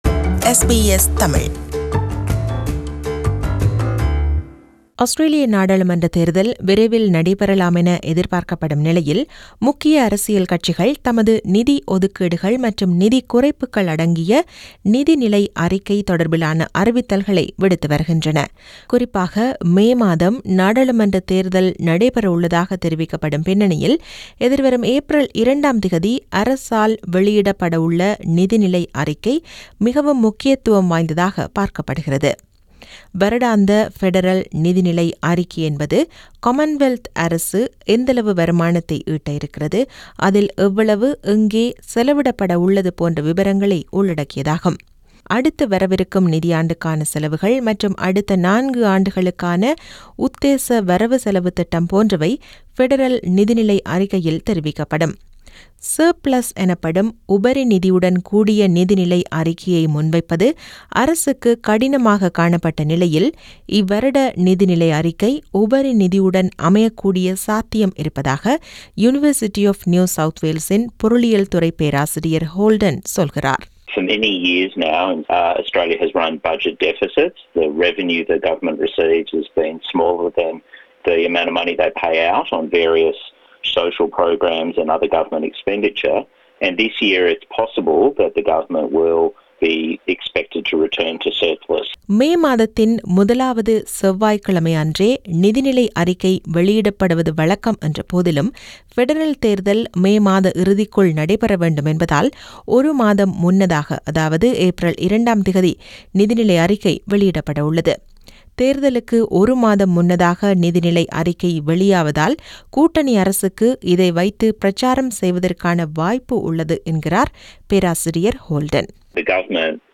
மே மாதம் நாடாளுமன்ற தேர்தல் நடைபெறலாம் என எதிர்வுகூறப்பட்டுள்ள பின்னணியில் எதிர்வரும் ஏப்ரல் 2ம் திகதி அரசால் வெளியிடப்பட்டுள்ள நிதிநிலை அறிக்கை முக்கியத்துவம் வாய்ந்ததாக பார்க்கப்படுகிறது. இதுகுறித்த விவரணம்.